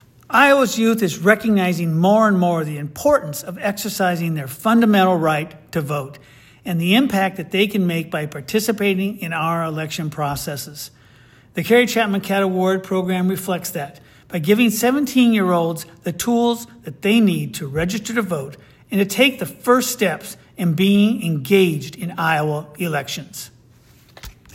A soundbite  from Secretary Pate is available for your use here.